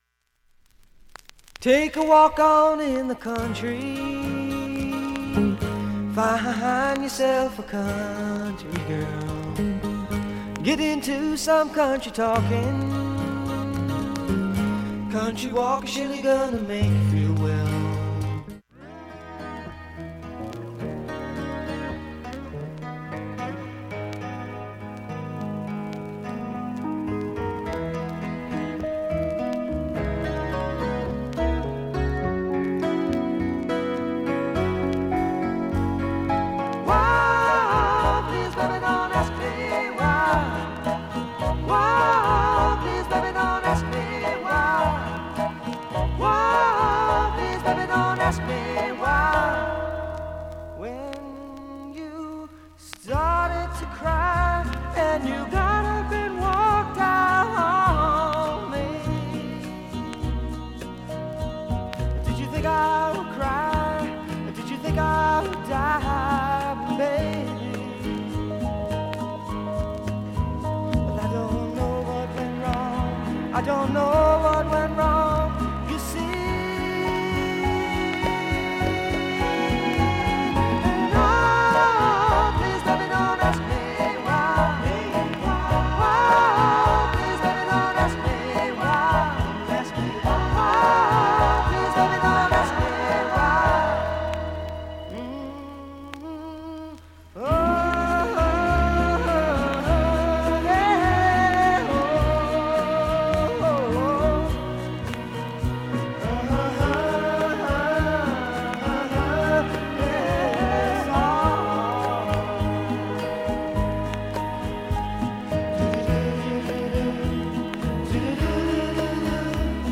基本いい音質です。
５回までのかすかなプツが２箇所
３回までのかすかなプツが３箇所
単発のかすかなプツが８箇所
フォーキー・メロウ名曲の